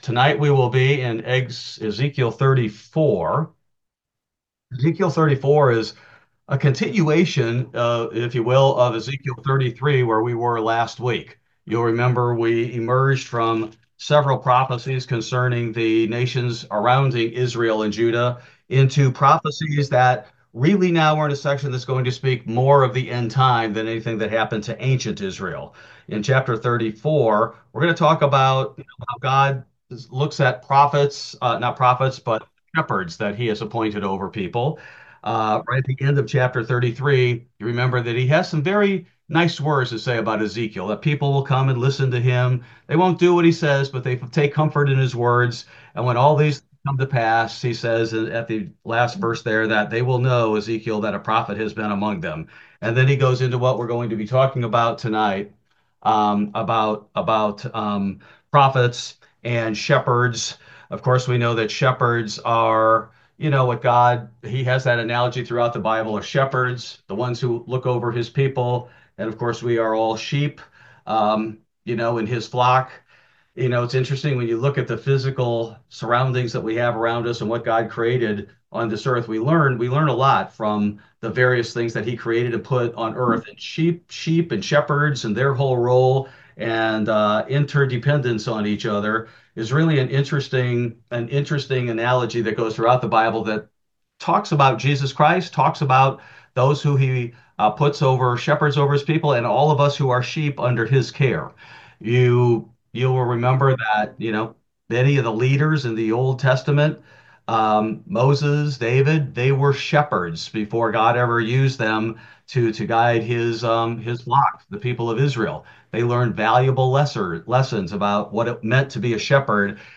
Ezekiel Bible Study: February 5, 2025